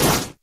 default_snow_footstep.3.ogg